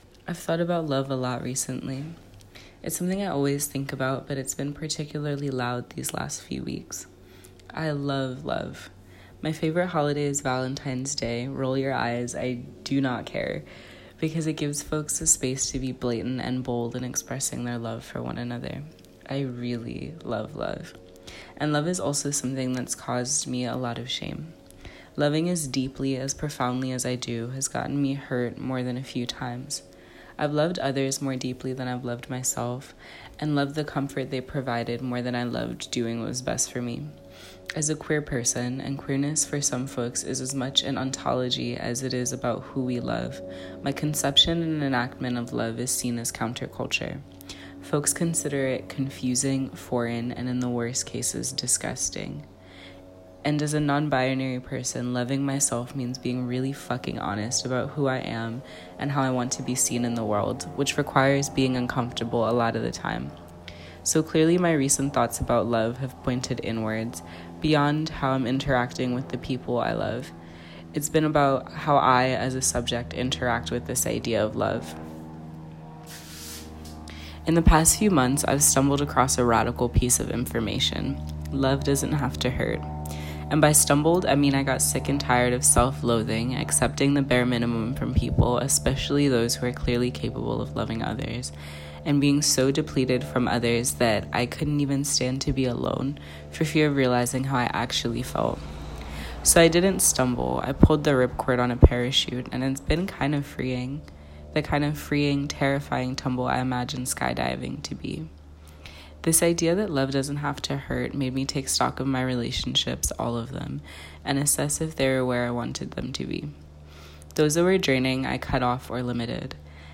[This was recorded on our porch while in Guadeloupe. Sorry for any plane sounds, dogs barking, or any other background noise.